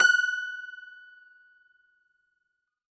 53q-pno20-F4.aif